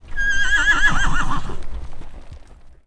骑士的马下令进攻
啾啾